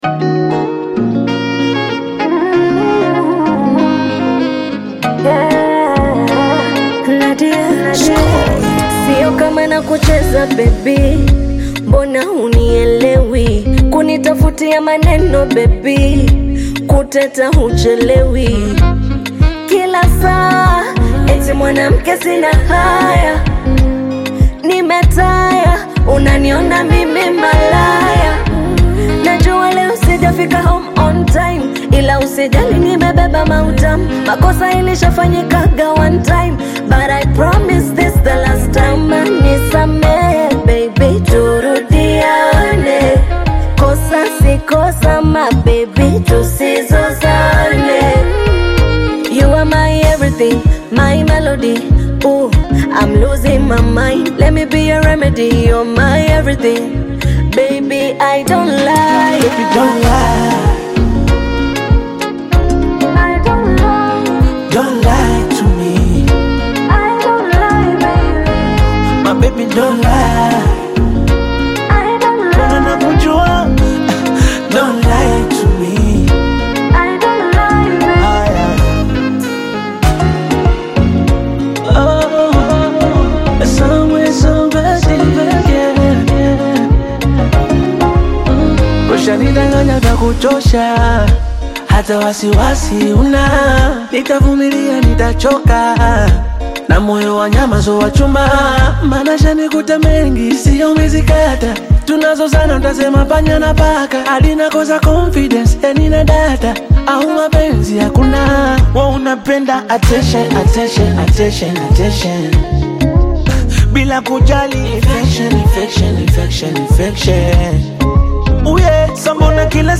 Afro-Beat/Bongo Flava collaboration
Genre: Bongo Flava